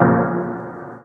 Atmos Dub Piano 1.wav